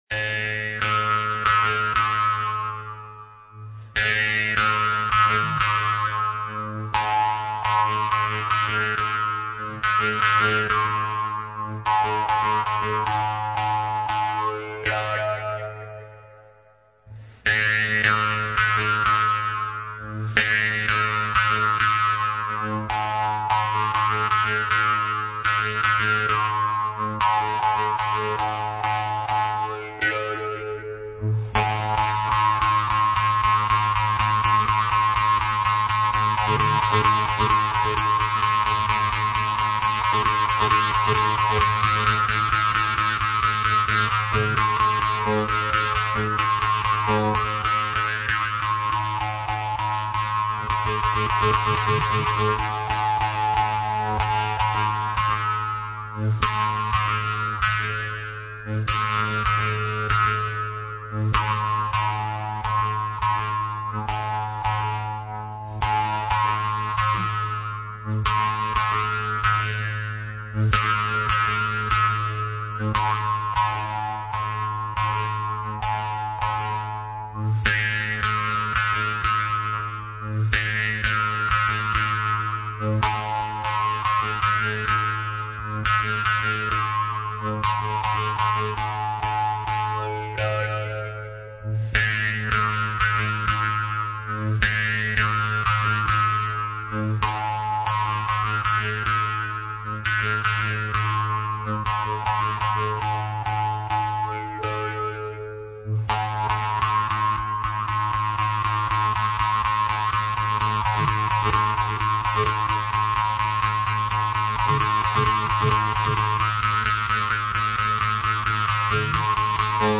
Народный шанкобызовый кюй
Шанкобыз